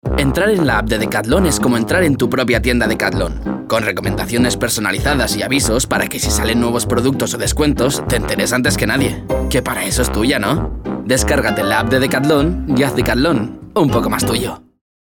sehr variabel
Jung (18-30)
Eigene Sprecherkabine
Narrative